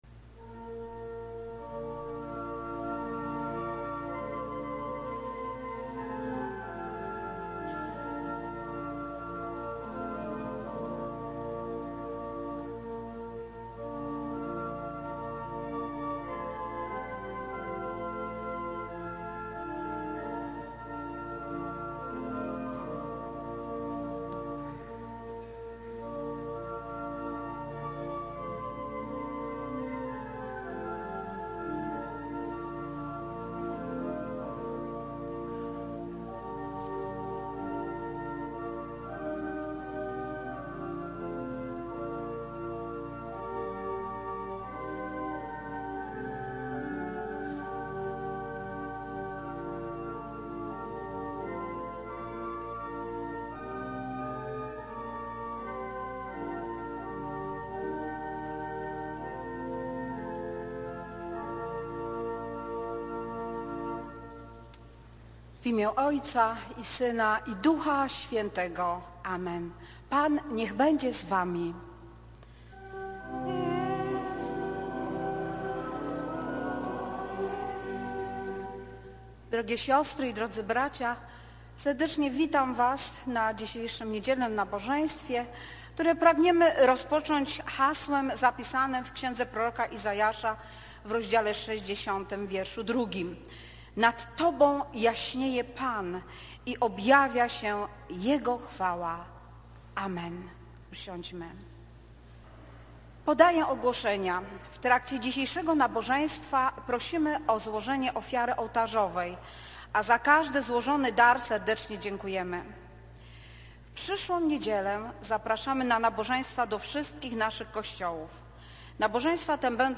3 NIEDZIELA PO WIELKANOCY